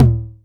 • Low Mid TR 909 Analog Tom A Key 33.wav
Royality free tr 909 electronic tom sample tuned to the A note. Loudest frequency: 259Hz